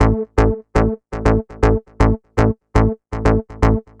TSNRG2 Bassline 023.wav